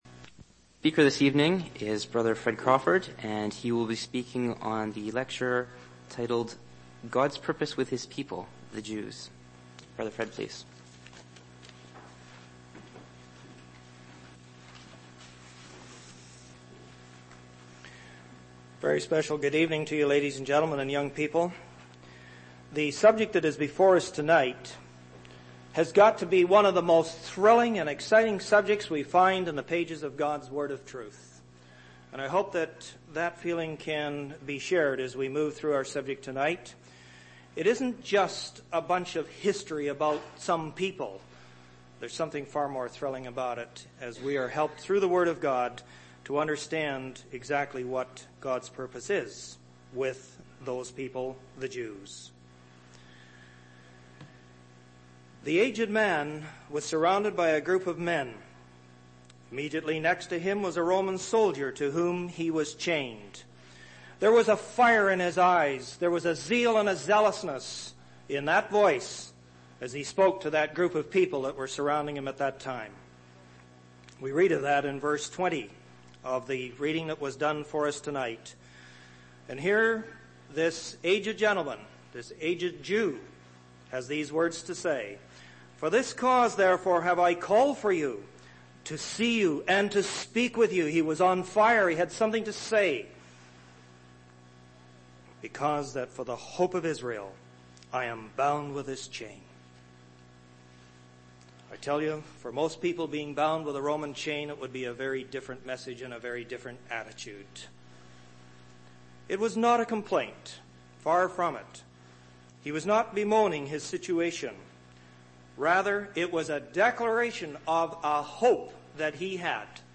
Public Talks